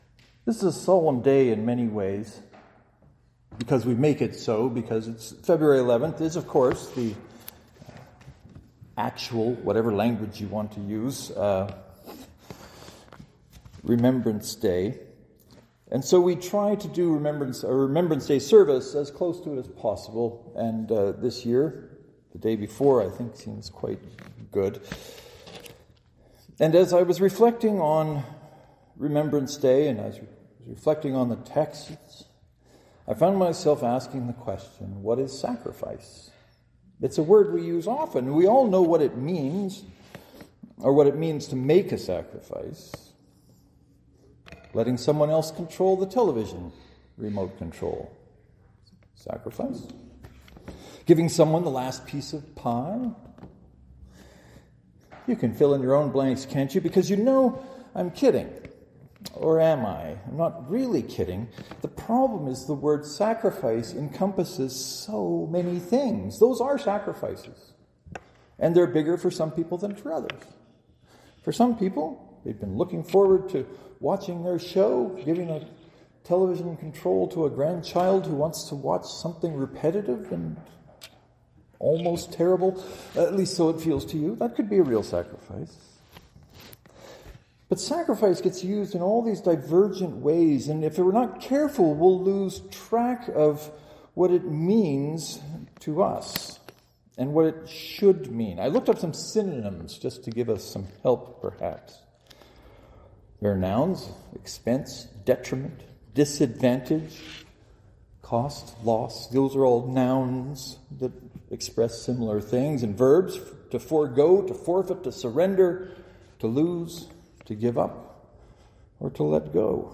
“What is sacrifice?” St. Mark’s Presbyterian (to download, right-click and select “Save Link As . . .”) In some ways this sermon didn’t go the way I was thinking would go or where I was planning on taking it but sometimes that’s the best sermon of all.